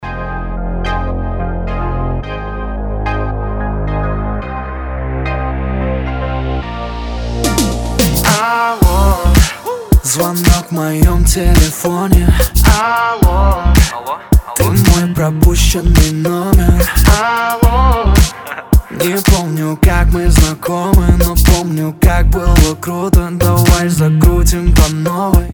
поп
мужской вокал
dance